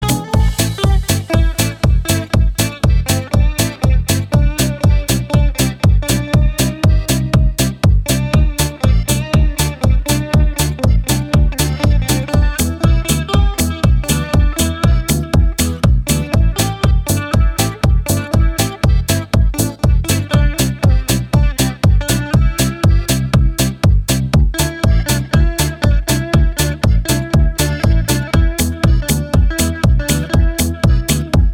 • Качество: 320, Stereo
без слов
инструментальные
Танцевальный и инструментальный звонок для Вашего мобильного